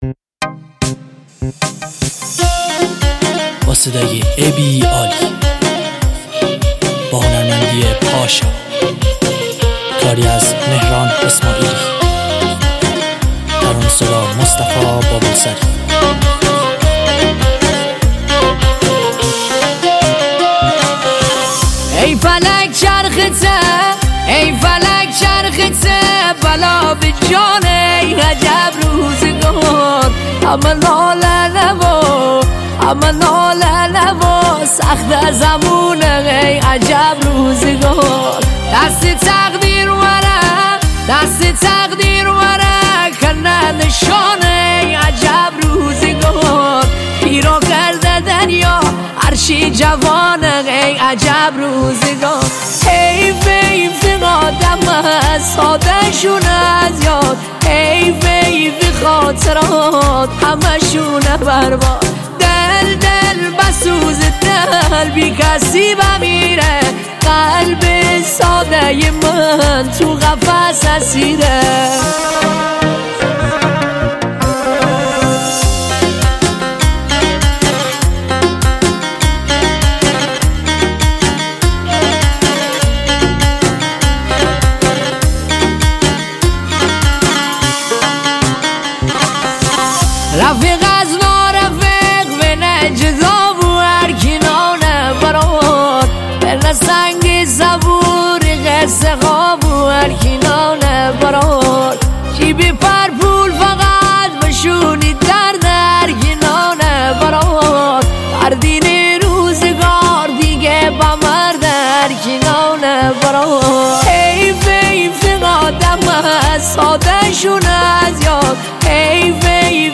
آهنگ مازندانی